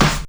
Snare (39).wav